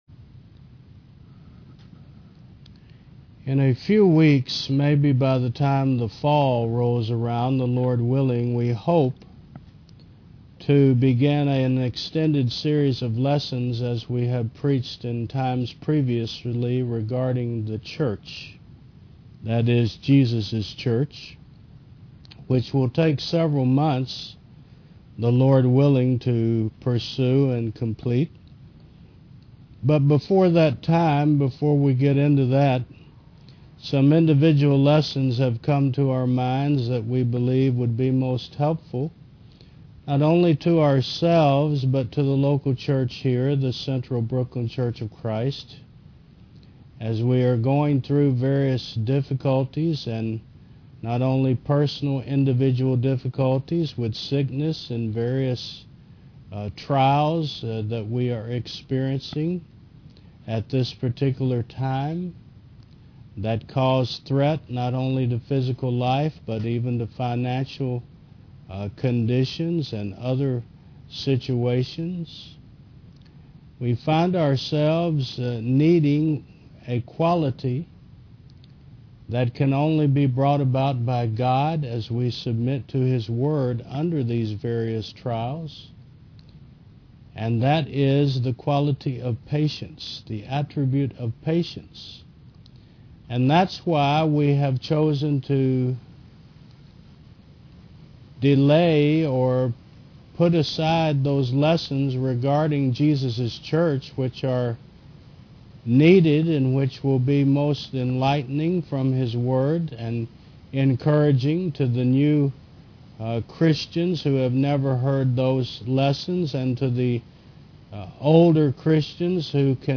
Patience v2 Service Type: Sun. 11 AM In a sinful world